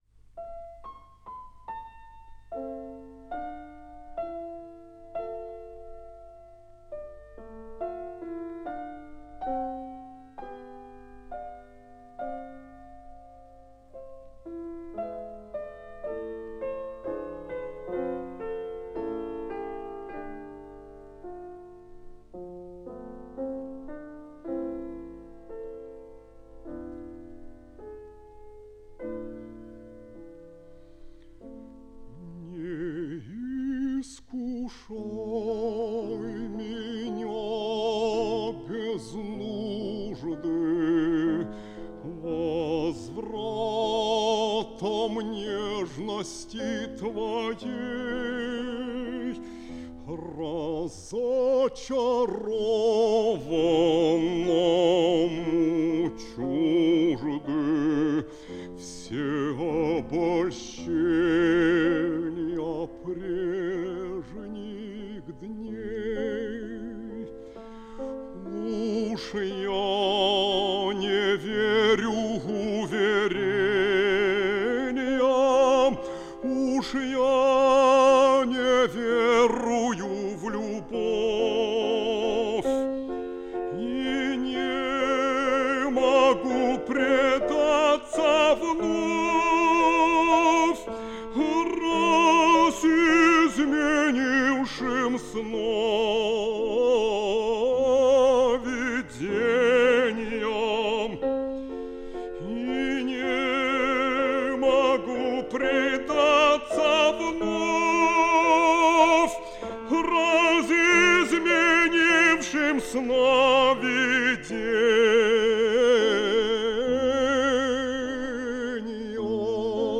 фп.) - Не искушай меня без нужды (М.Глинка - Е.Баратынский) (1952)